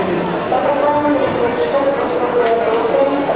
The bazaar in Osh is one of the largest and oldest in Central Asia.
It is a hustling, crowded place and you can find anything from fresh fruits and vegetables to horseshoes and saddles to clothes, household items and souvenirs.
oshbazaar.wav